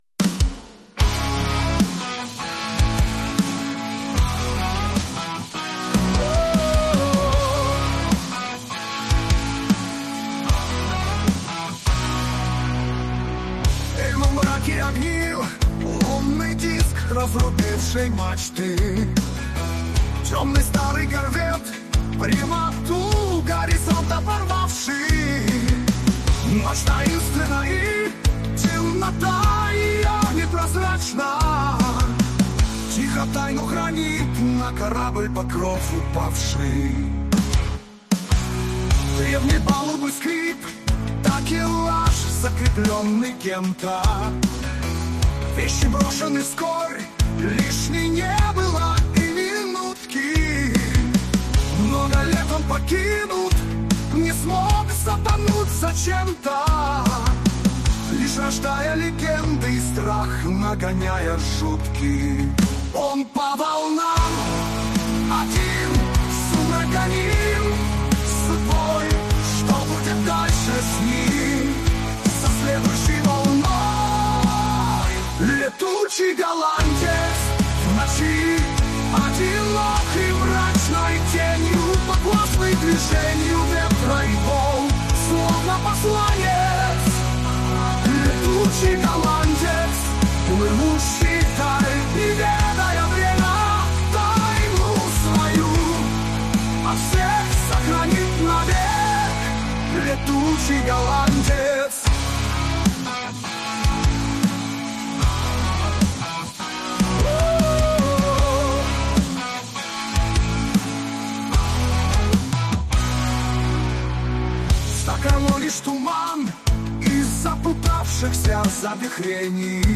Стильно и атмосферно.